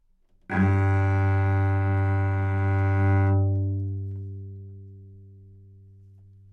大提琴单音（其他大提琴录音） " 大提琴 G2 其他
描述：在巴塞罗那Universitat Pompeu Fabra音乐技术集团的goodsounds.org项目的背景下录制。单音乐器声音的Goodsound数据集。
标签： 纽曼-U87 单注 大提琴 多重采样 好声音 G2
声道立体声